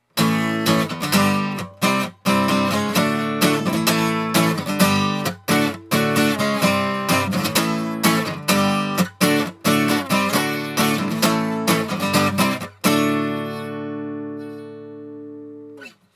This guitar has a very tight, very articulate tone that is best described as focused.
Barre Chords
Since this is an acoustic guitar without electronics, I recorded it with my trusty Olympus LS10 recorder. I put the recorder on my desk (on a foam pad) and positioned the guitar with the sound hole about 18″ from the mics.